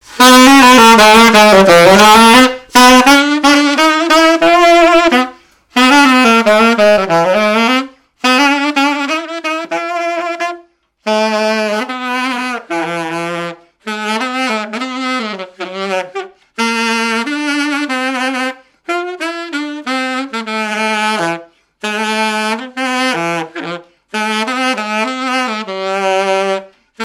Marche de noce
activités et répertoire d'un musicien de noces et de bals
Pièce musicale inédite